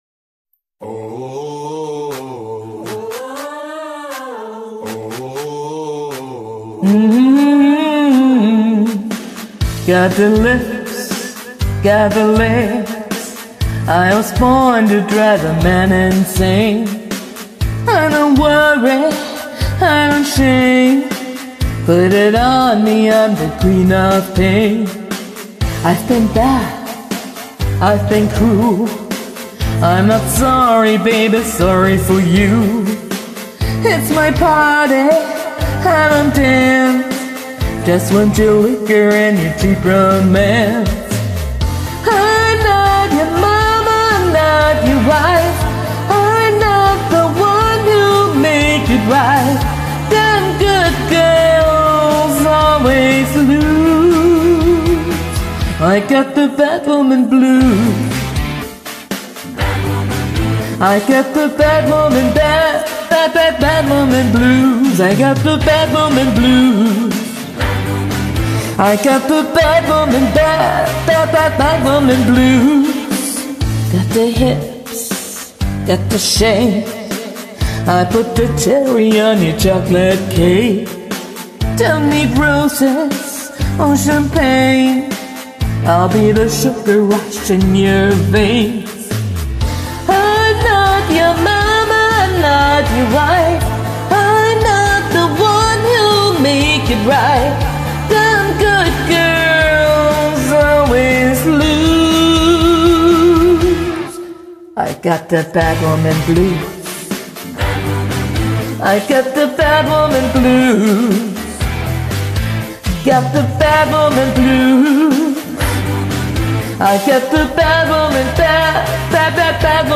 Blues & Rock